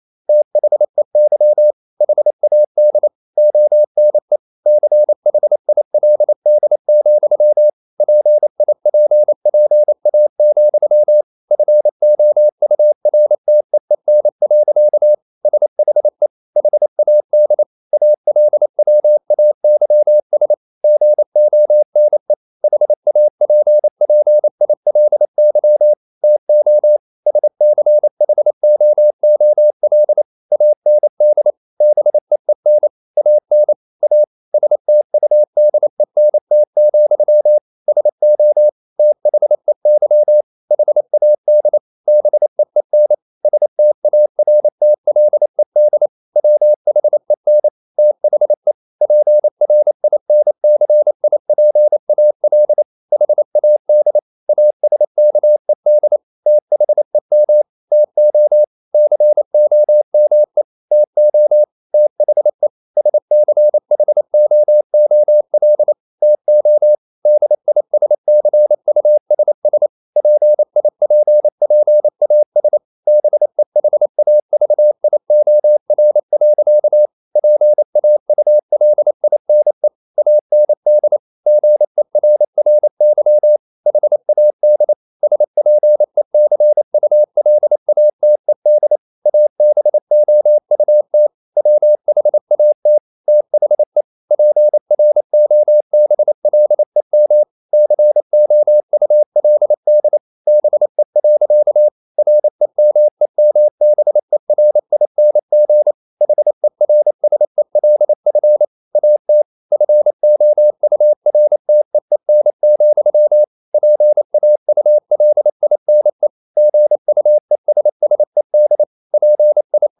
25 - 29 wpm | CW med Gnister
Romanen Never af Ken Follet på engelsk. LYD FIL: Never_0028wpm.mp3 Hastighed: 25 - 29 wpm Sprog: Engelsk Rate: Select rating Give Never 28wpm 1/5 Give Never 28wpm 2/5 Give Never 28wpm 3/5 Give Never 28wpm 4/5 Give Never 28wpm 5/5 No votes yet